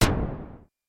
Fender Chroma Polaris Storm Drum " Fender Chroma Polaris Storm Drum F4 (67 G3SD87)
标签： MIDI网速度52 FSharp4 MIDI音符-67 挡泥板-色度北极星 合成器 单票据 多重采样
声道立体声